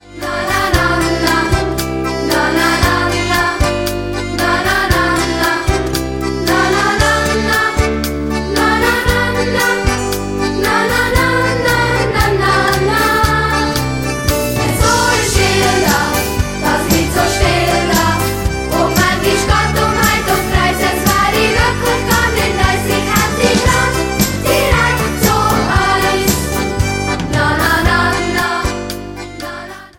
Musical-Album